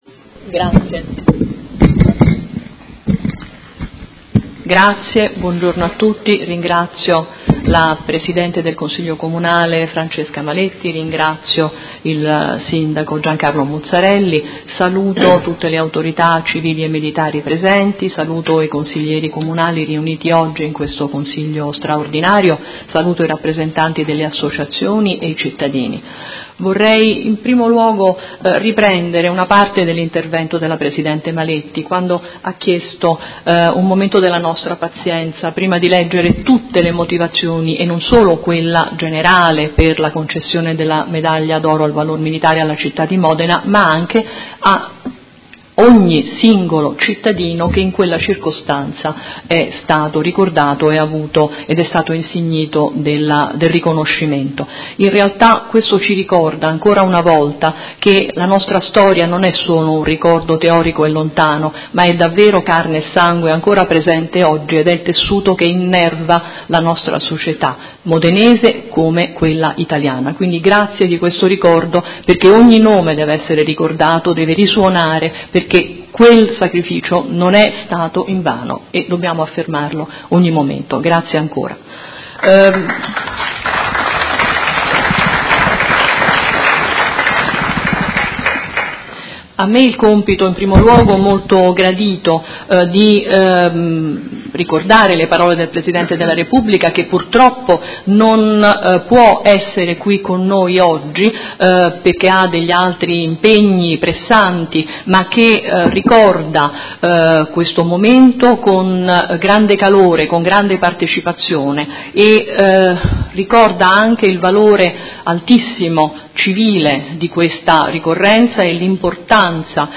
Seduta del 08/12/2017 Modena Medaglia d'oro al Valor Militare. 70° anniversario.
Maria Patrizia Paba, Prefetta di Modena